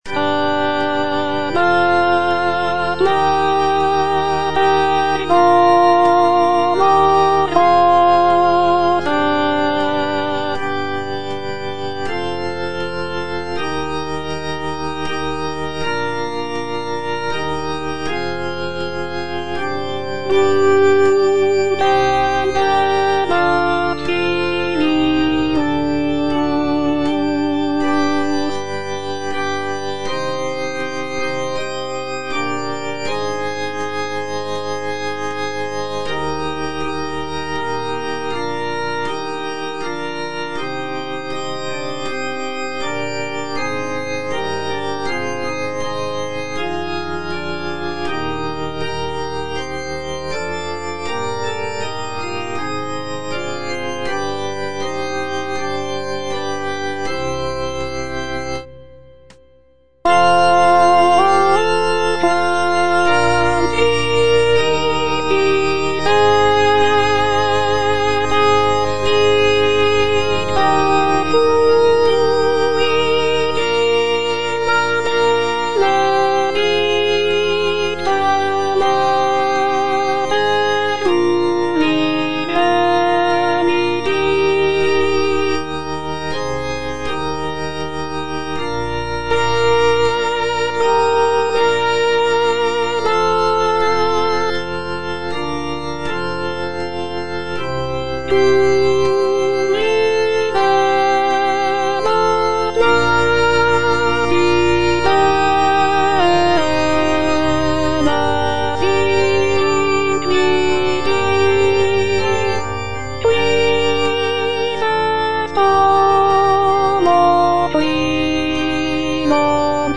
G.P. DA PALESTRINA - STABAT MATER Stabat Mater dolorosa (alto I) (Voice with metronome) Ads stop: auto-stop
sacred choral work